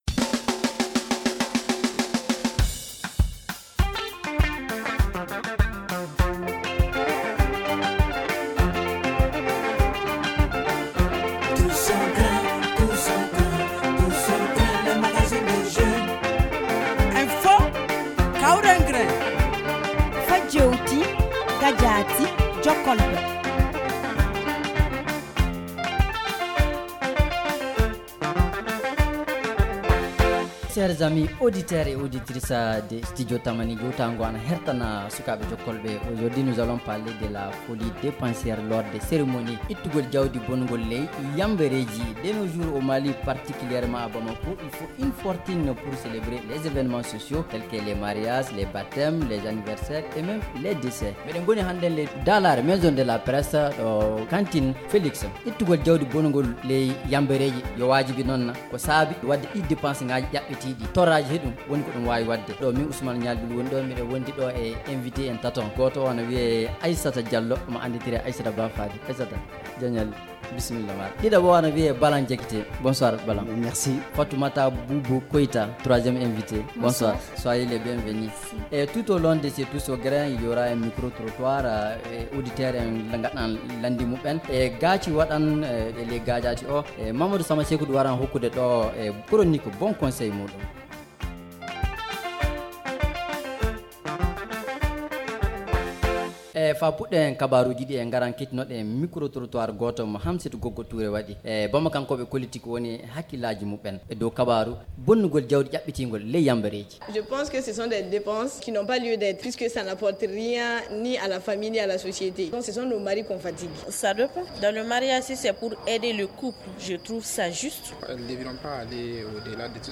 De nos jours, au Mali particulièrement à Bamako, il faut une fortune pour célébrer les événements sociaux tels que les mariages, les baptêmes, les anniversaires, et même les décès. Cette semaine, le Grin n’est pas allé loin, La team s’est posée dans l’enceinte de la maison de la presse pour échanger sur cette pratique.